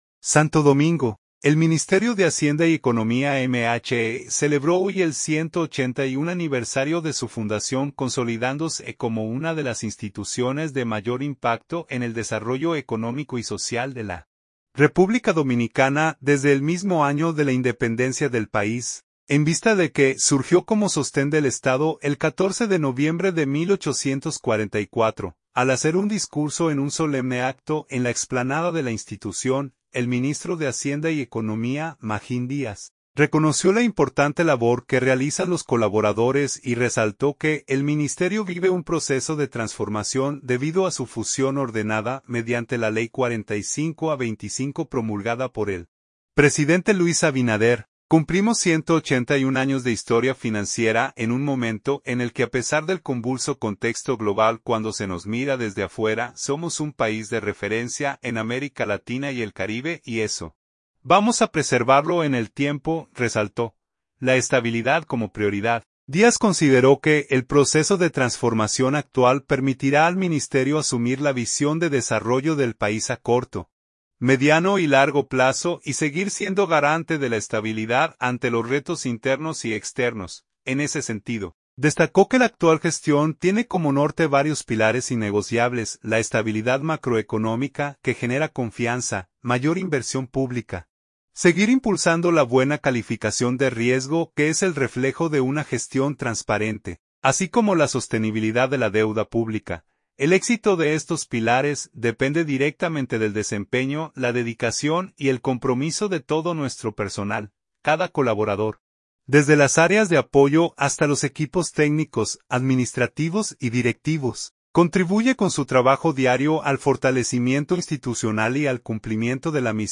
Al ofrecer un discurso en un solemne acto en la explanada de la institución, el ministro de Hacienda y Economía, Magín Díaz, reconoció la importante labor que realizan los colaboradores y resaltó que el Ministerio vive un proceso de transformación debido a su fusión ordenada mediante la Ley 45-25 promulgada por el presidente Luis Abinader.